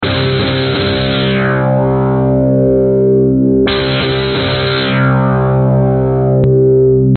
描述：带自动滤波器的失真Fender Stratocaster吉他
Tag: 电子 时髦 吉他 循环 技术